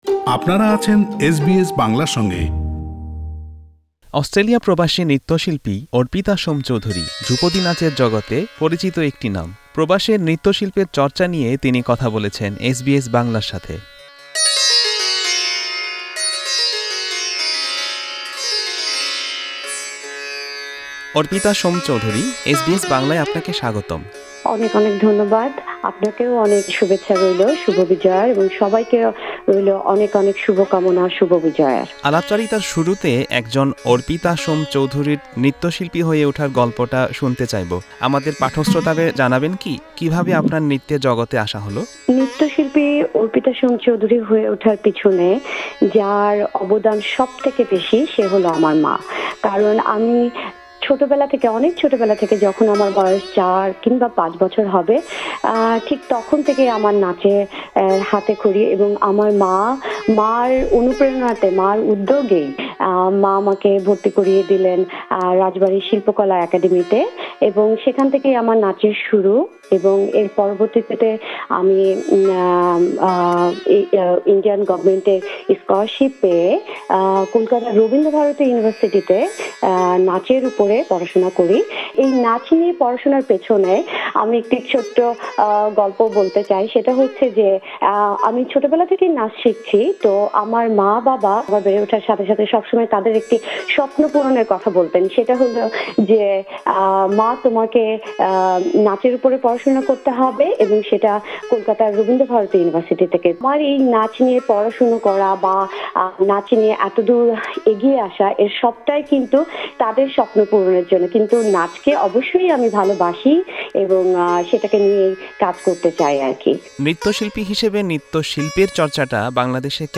এসবিএস বাংলা